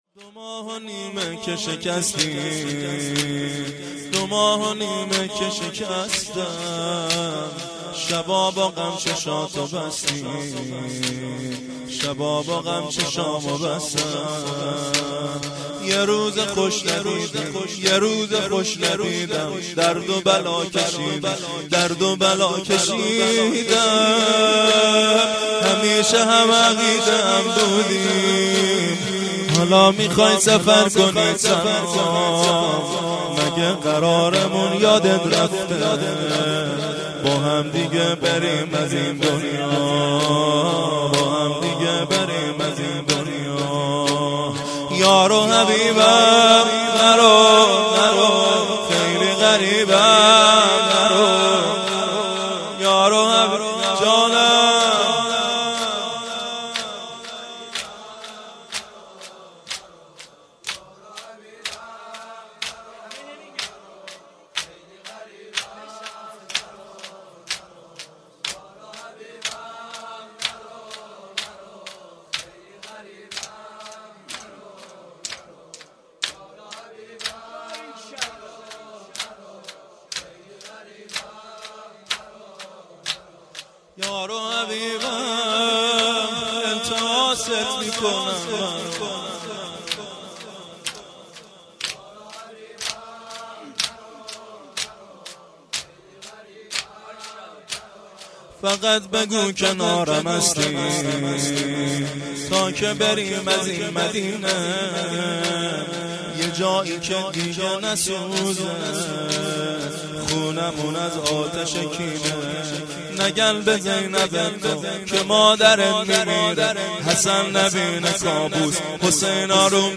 هیئت زواراباالمهدی(ع) بابلسر
جلسه هفتگی